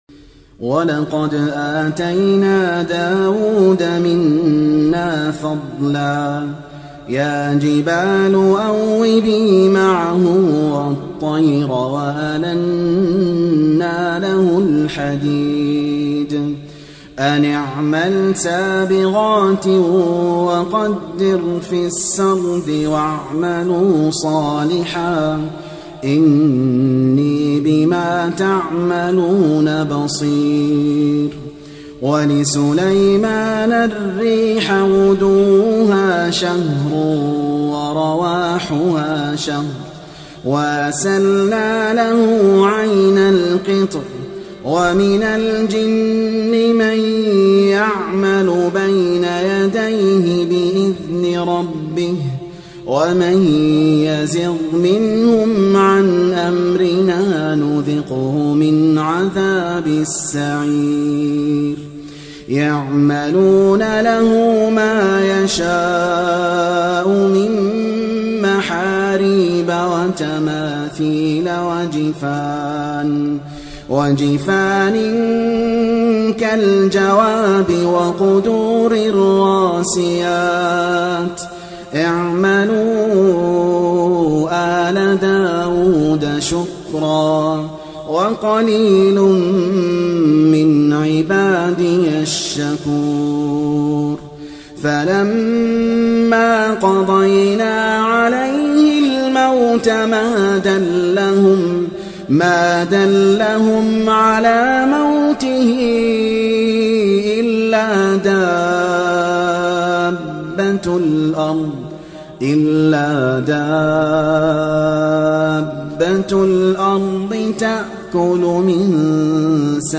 تلاوة من سبأ